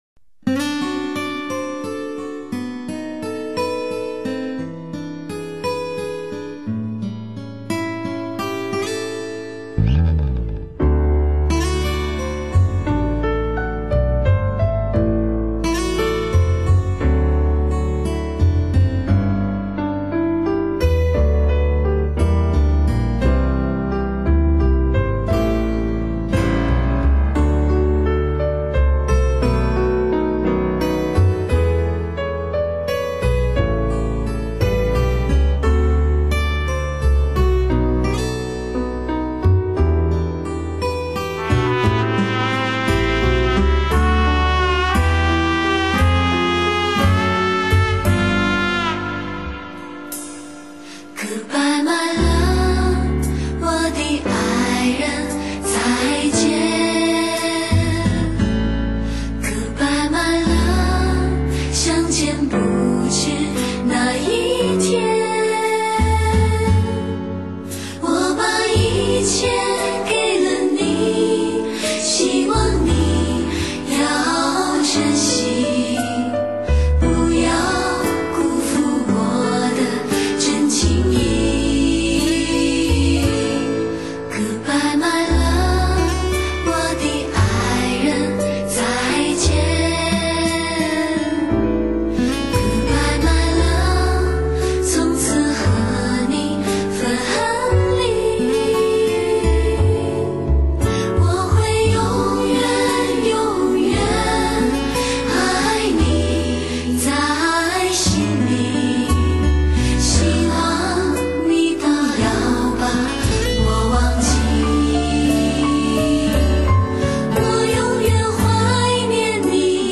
surround6.1 3D音效HI-FINEW AGE
全方位360度环绕HI-FI AUTO SOUND 专业天碟！打